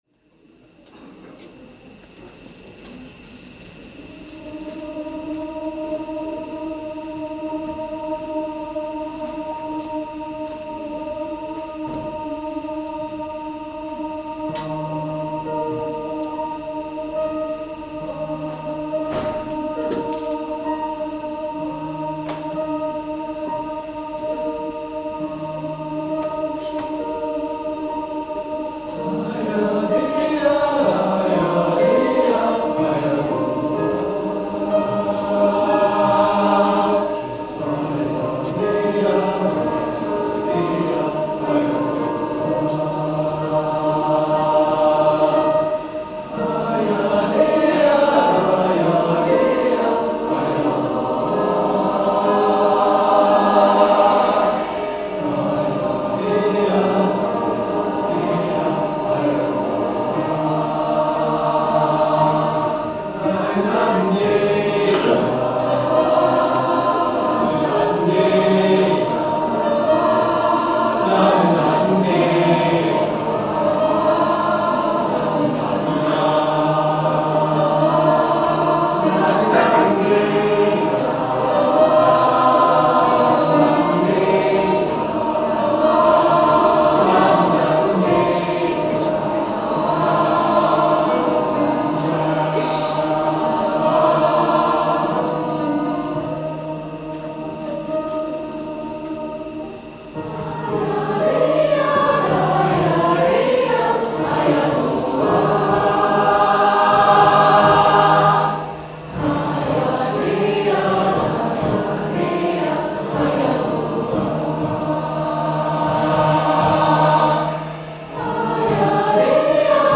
09 - Konzertaufnahmen - ChorArt zwanzigelf - Page 6
ChorArt zwanzigelf – das sind junge und jung gebliebene Sängerinnen und Sänger, die nicht nur Spaß in der Probe, sondern auch auf der Bühne haben.
Wir sind laut, leise, kraftvoll, dynamisch, frisch, modern, bunt gemischt und alles, nur nicht langweilig!